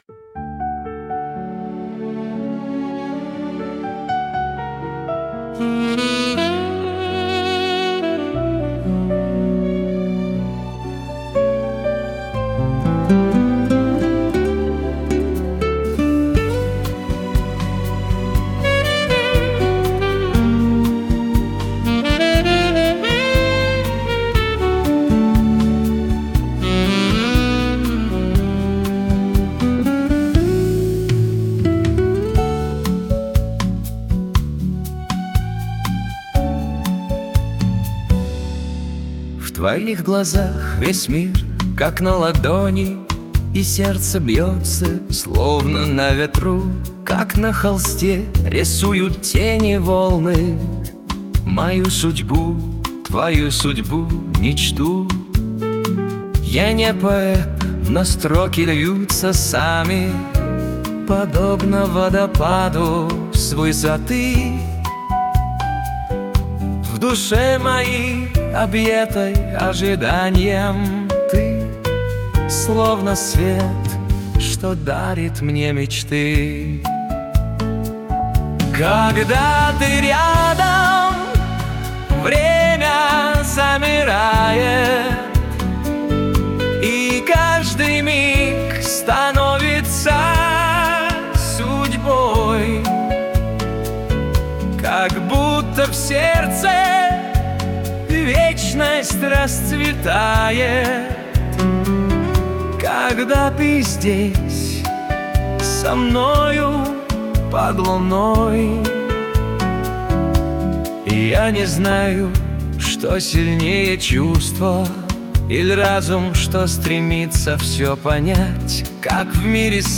• Жанр: Классика
• Аранжировка: Generative Audio Workstation Suno Platform